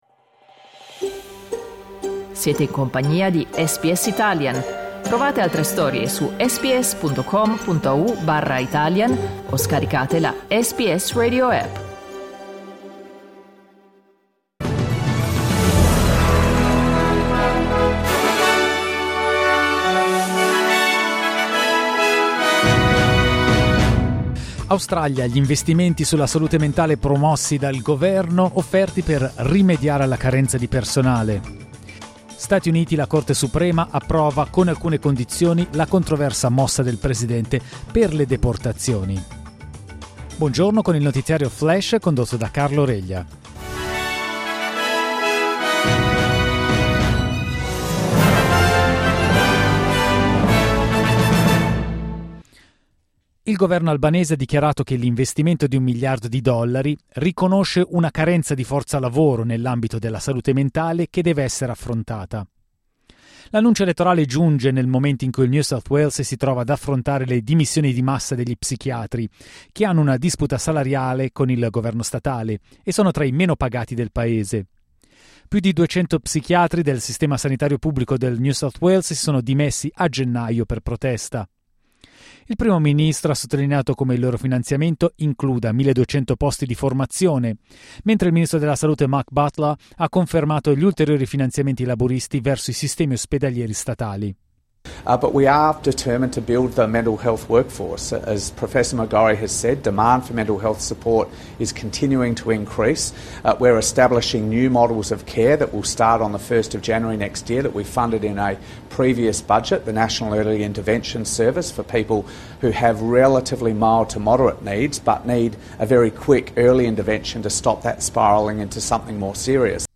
News flash martedì 8 aprile 2025